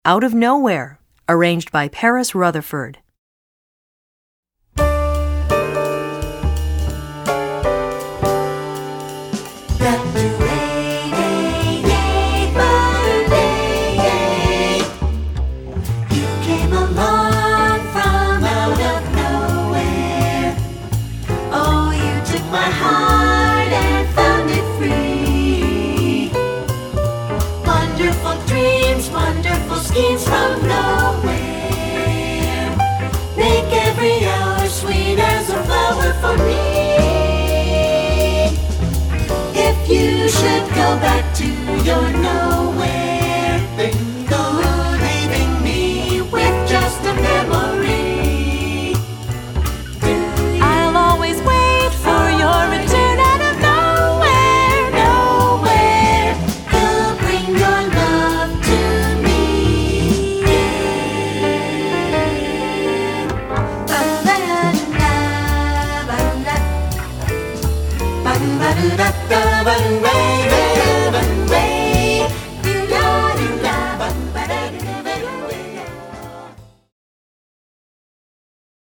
Choeur SAB